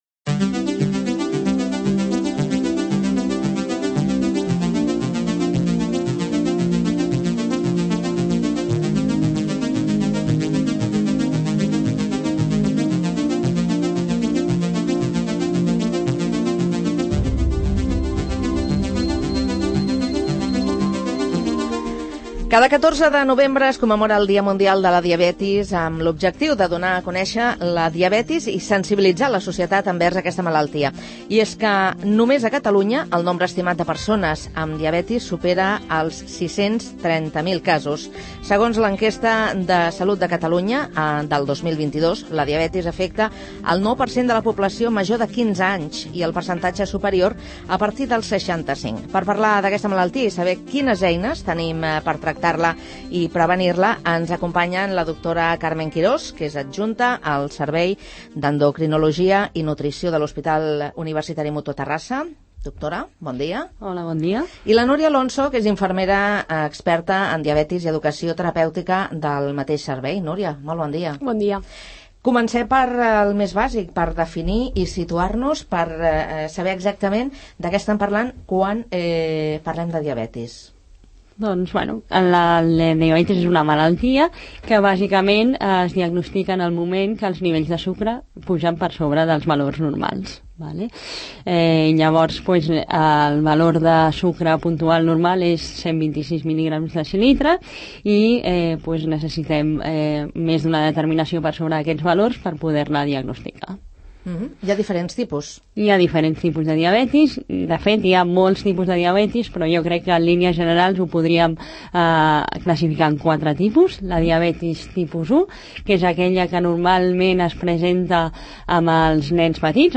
a l’estudi Ramon Barnils de Ràdio Sant Cugat / Cugat Mèdia